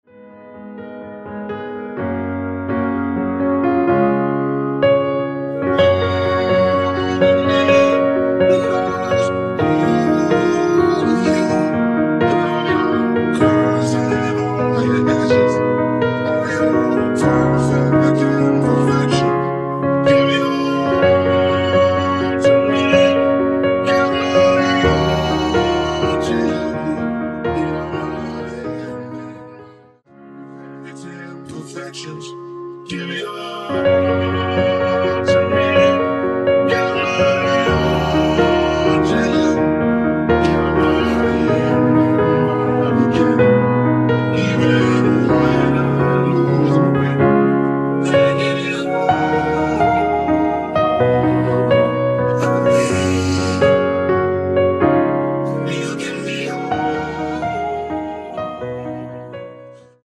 원키에서(-2)내린 멜로디와 코러스 포함된 MR입니다.
Gb
앞부분30초, 뒷부분30초씩 편집해서 올려 드리고 있습니다.
중간에 음이 끈어지고 다시 나오는 이유는